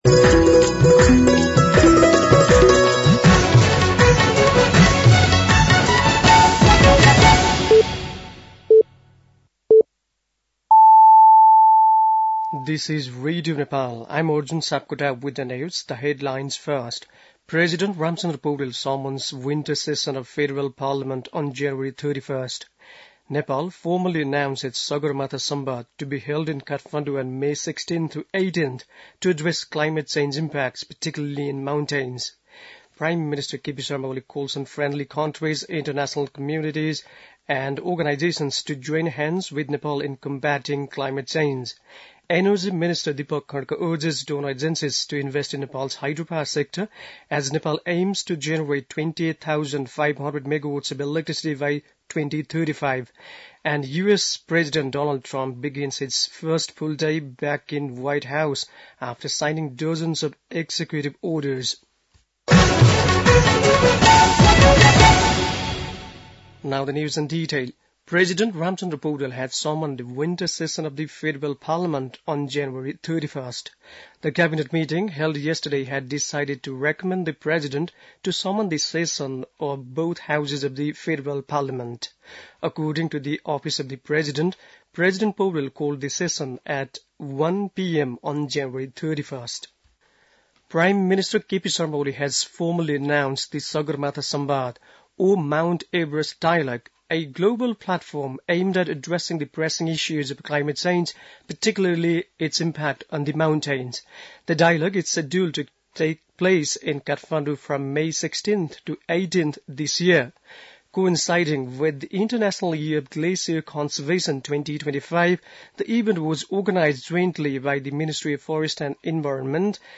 बेलुकी ८ बजेको अङ्ग्रेजी समाचार : ९ माघ , २०८१